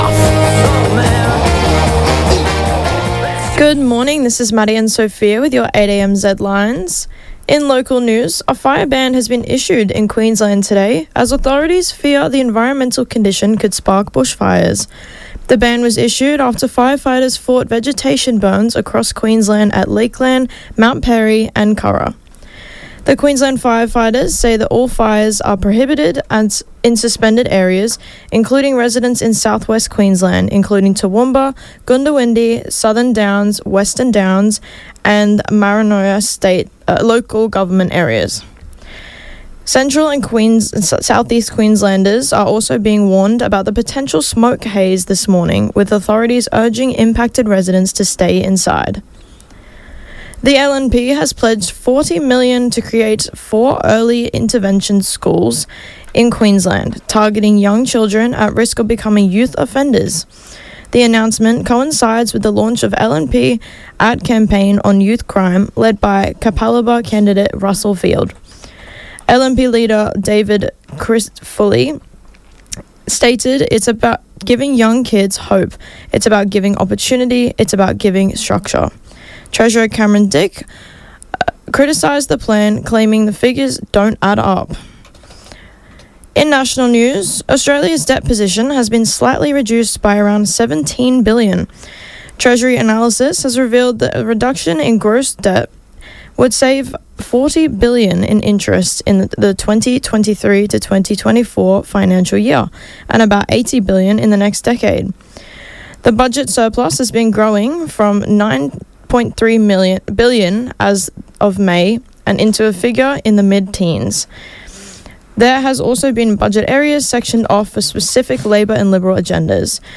Zedlines Bulletin 23.09 8 am.mp3 (5.39 MB)